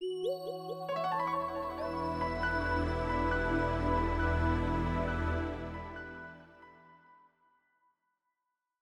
Longhorn 9X - Startup.wav